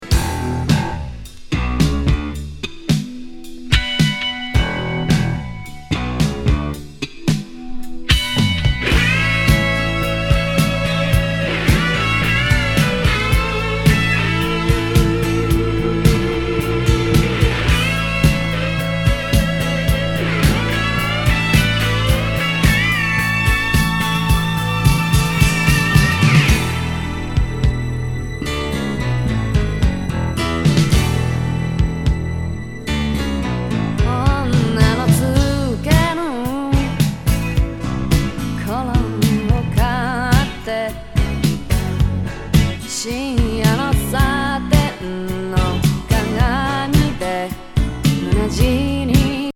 グルーヴィーAOR